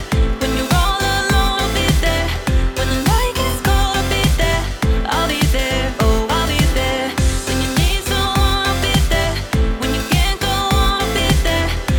I used 8x oversampling for all examples and left the output gain control untouched in all examples for comparison purposes.
AI Loudener (85% Transparent, 15% Warm) Drive 100% resulted in -12.2 LUFS